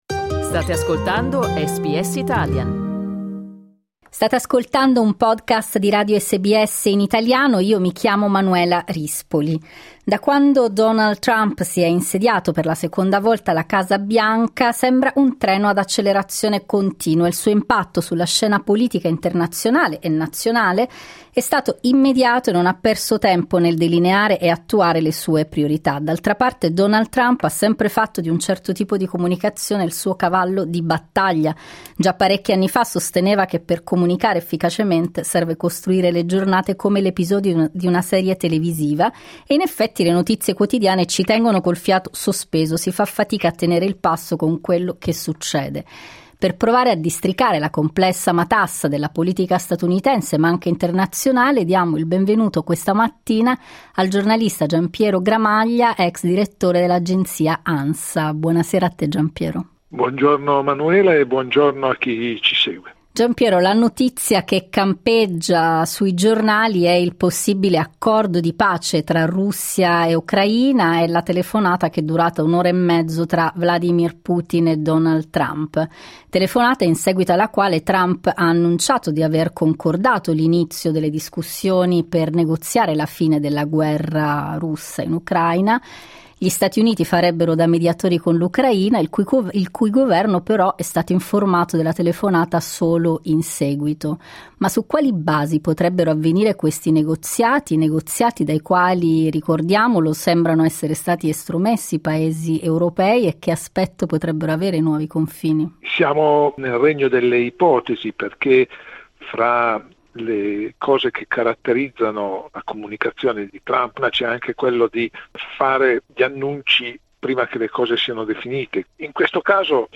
l'analisi del giornalista